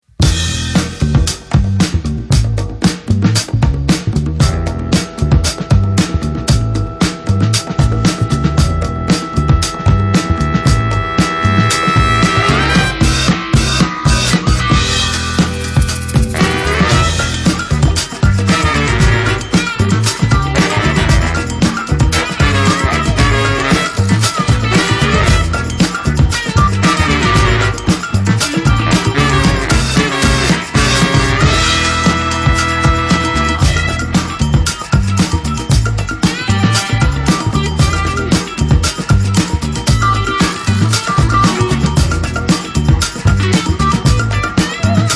a funk tune